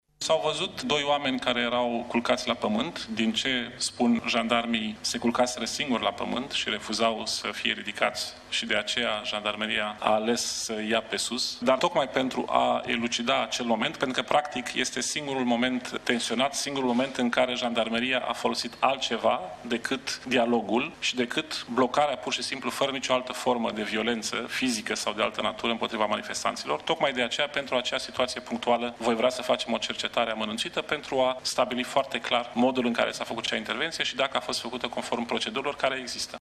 Ministrul Afacerilor Interne, Dragoș Tudorache, a precizat azi, în cadrul unei conferințe de presă, că a cerut conducerii Jandarmeriei declanșarea unei cercetări disciplinare prealabile pentru modul în care s-a folosit forța în timpul marșului de sâmbătă, subliniind că aceasta va viza strict momentul reținerii unor protestatari.